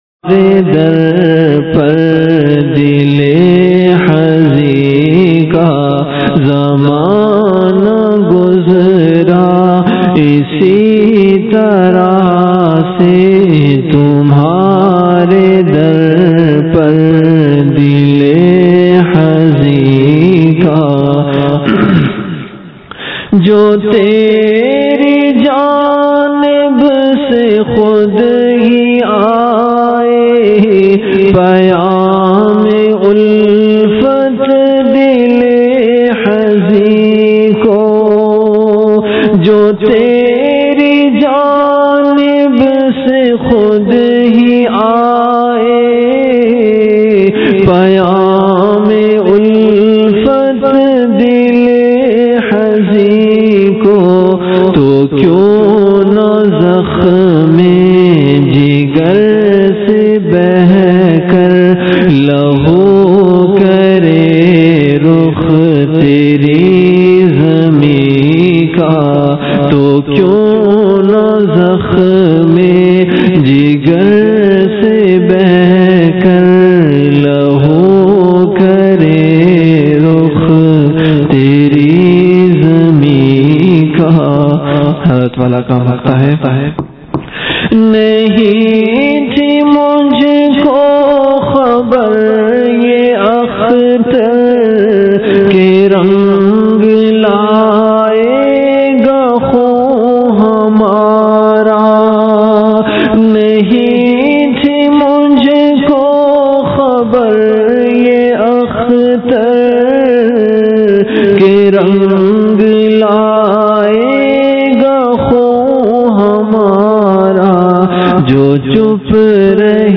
CategoryAshaar
VenueKhanqah Imdadia Ashrafia
Event / TimeAfter Asar Prayer